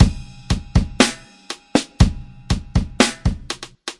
4/4节拍的踢球小鼓棍子骑马干变化120bpm " 44节拍的踢球小鼓干120bpm的棍子骑马04
Tag: 节奏 4 120bpm 节拍 鼓环 有节奏